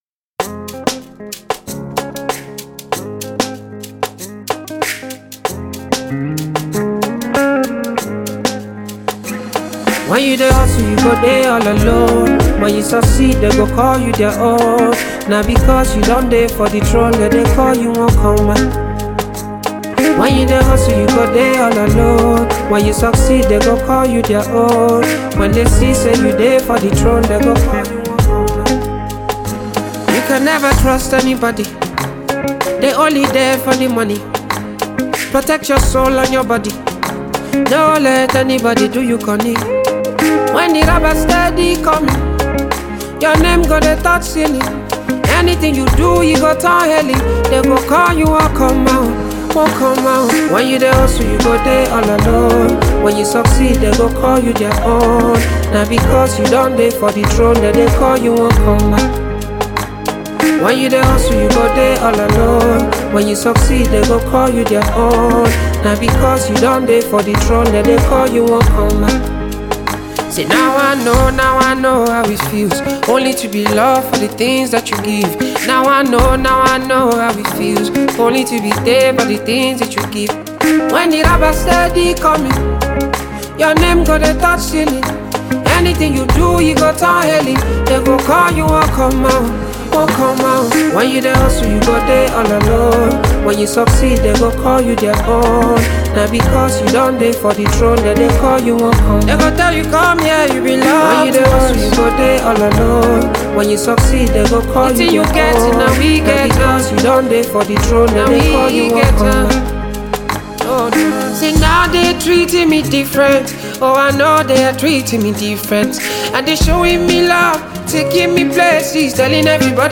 Talented R’n’B and Pop singer